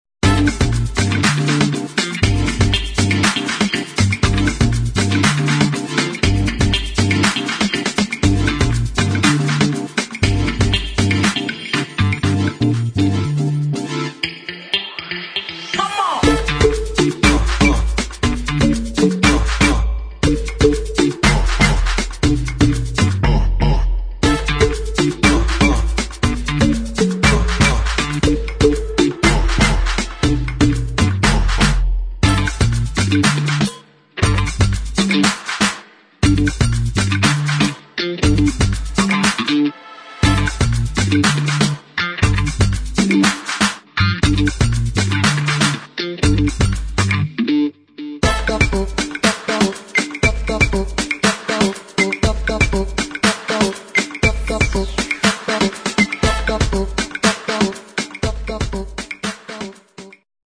[ AFRO FUNK | HOUSE | BREAKBEAT ]